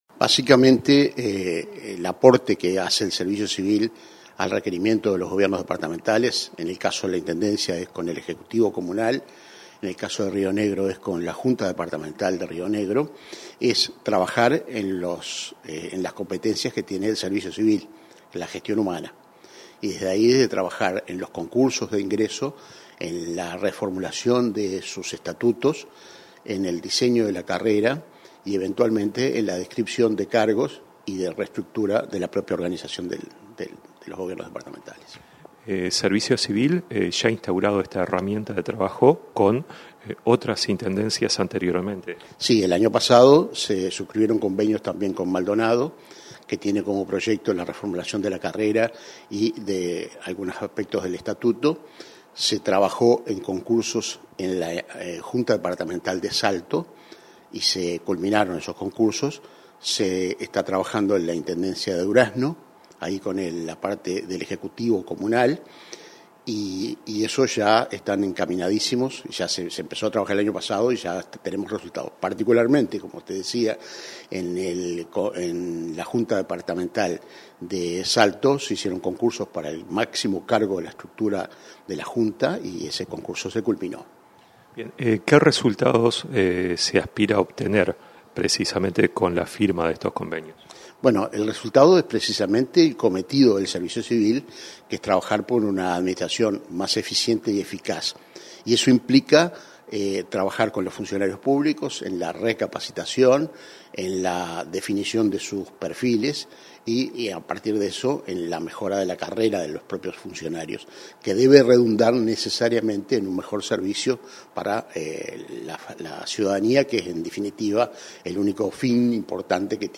Entrevista al subdirector de la ONCS, Ariel Sánchez